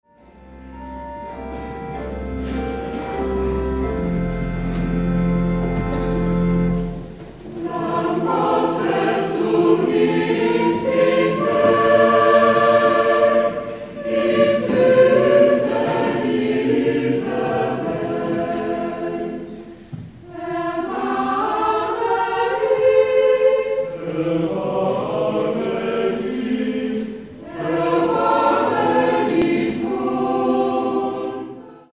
(Live-Aufnahmen)